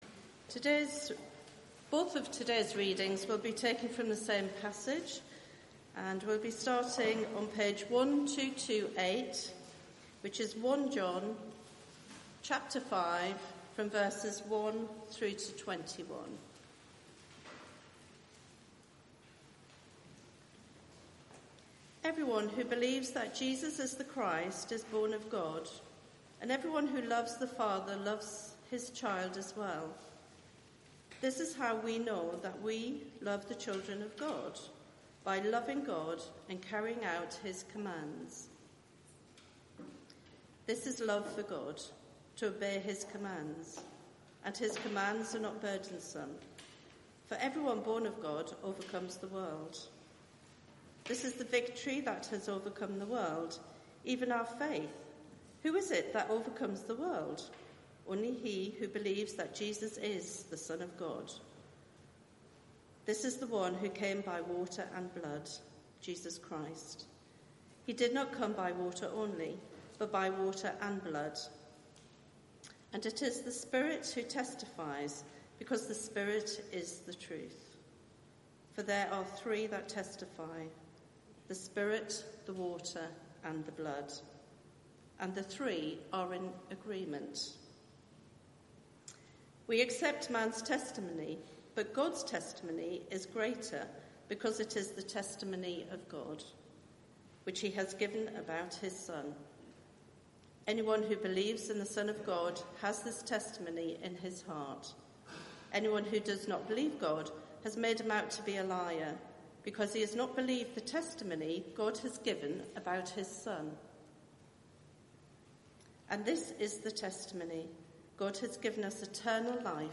Media for 9:15am Service on Sun 30th Jun 2019 09:15 Speaker
Sermon (11:00 Service) Search the media library There are recordings here going back several years.